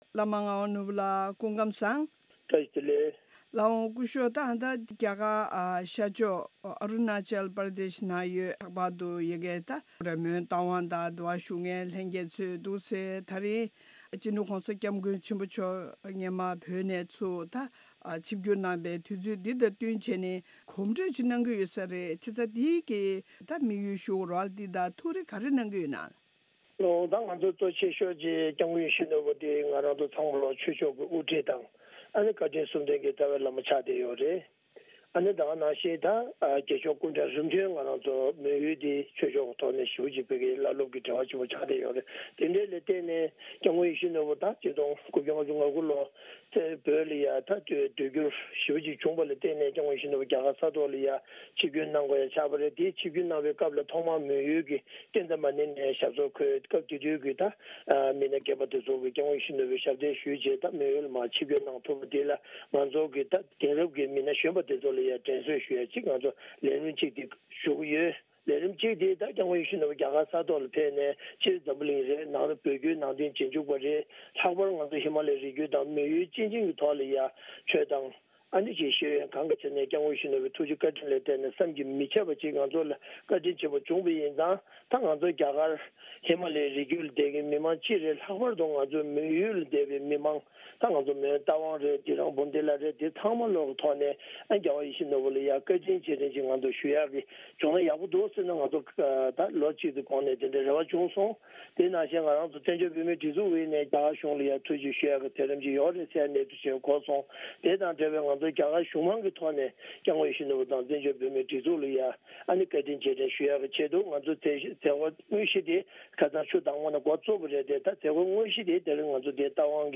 གནས་འདྲི་ཞུས་པ་དེ་གསན་རོགས་གནང་།།